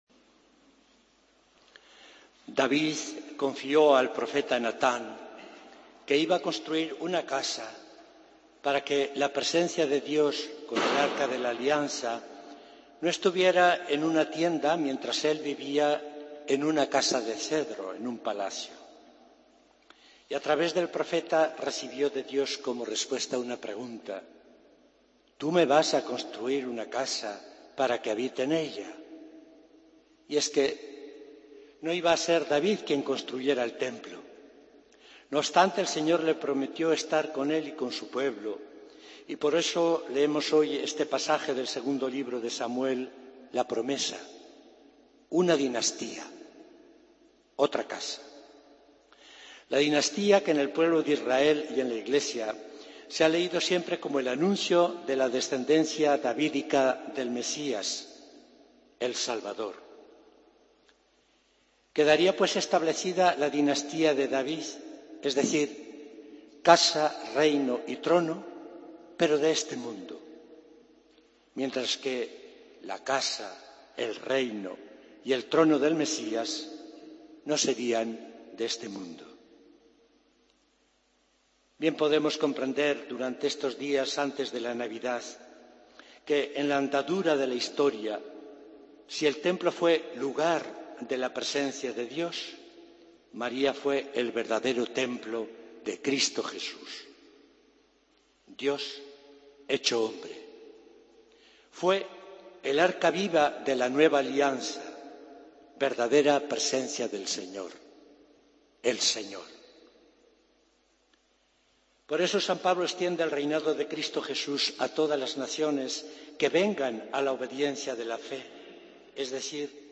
Homilía del Domingo 21 de Diciembre de 2014